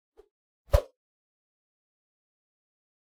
mandrake fvttdata/Data/modules/psfx/library/weapon-swooshes/light/v1/group03
meleeattack-swoosh-light-group03-00.ogg